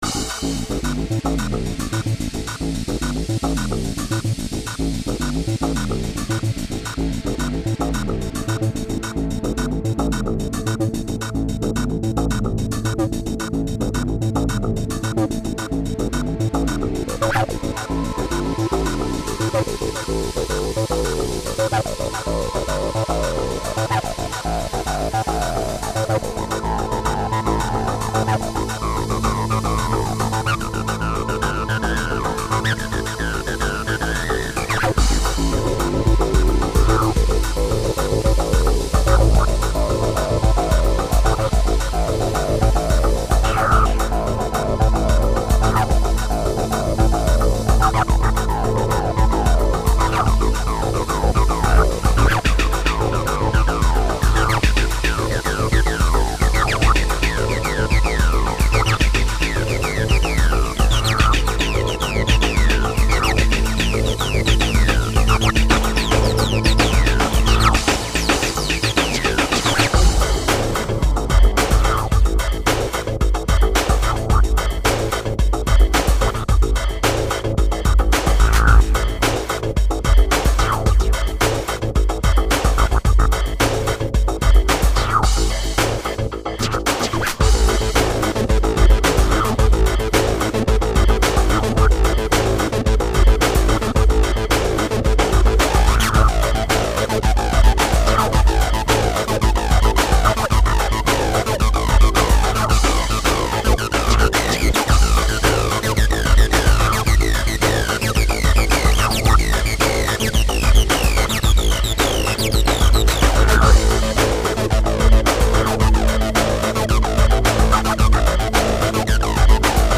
Acid without Roland's TB303
303_demo_-_acid_03.mp3